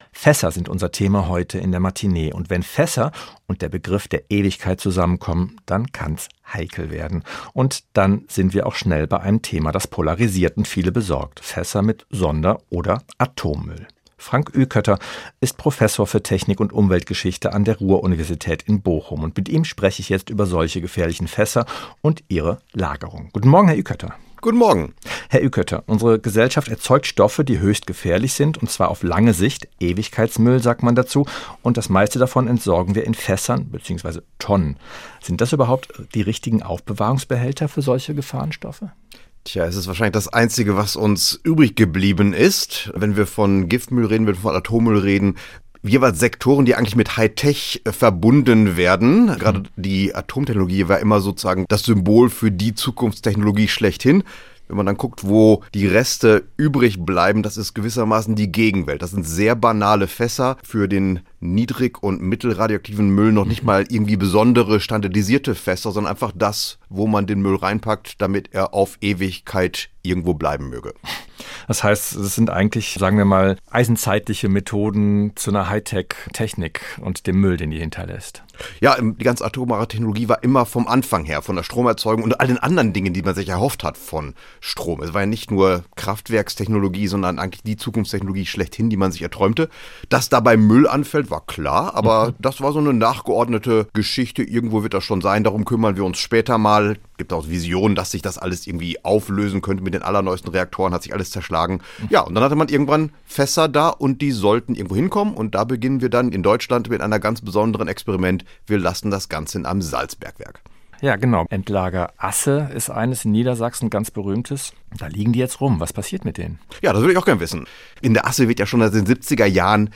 Von improvisierten Methoden bis hin zu Hightech-Lösungen: Ein Gespräch über Verantwortung, Risiken und die Suche nach einer dauerhaften Lösung.